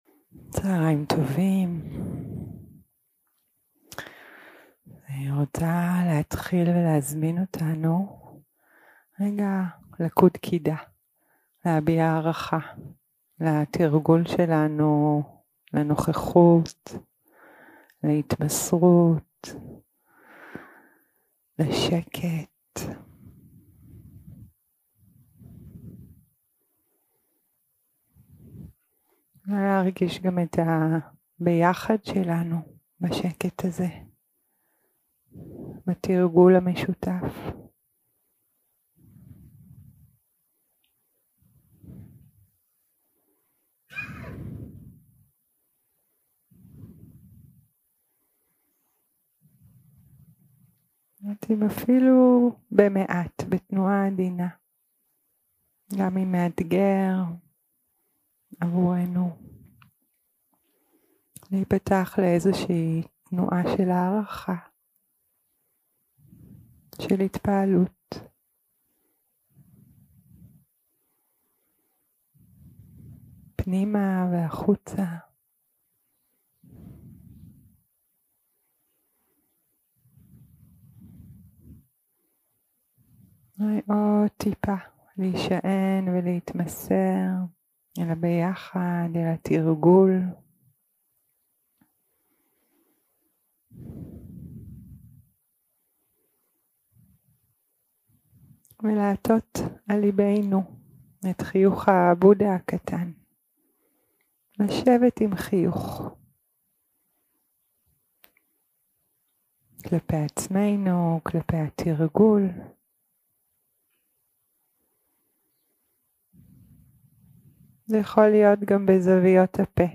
יום 3 – הקלטה 6 – צהריים – מדיטציה מונחית
Dharma type: Guided meditation